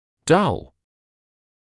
[dʌl][дал]тупой (о боле, звуке)